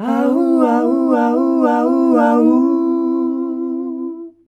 HAAUHAAU.wav